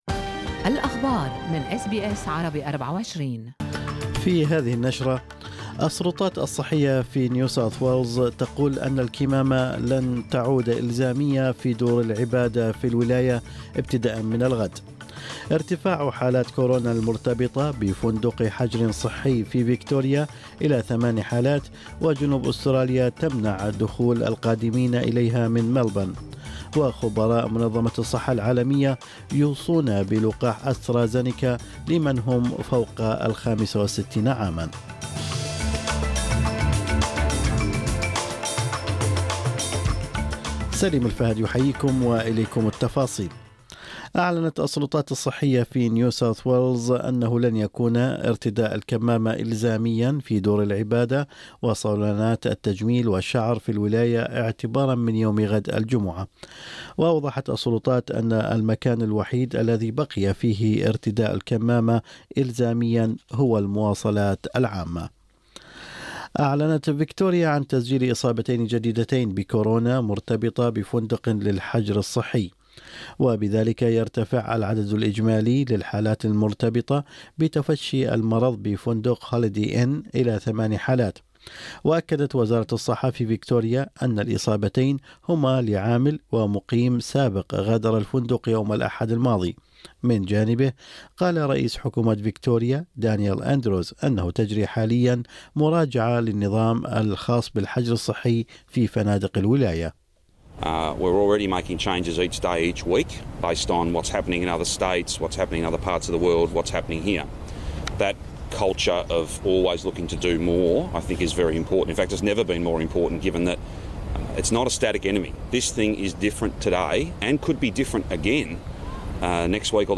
نشرة أخبار الصباح 11/2/2021